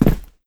jumpland5a.wav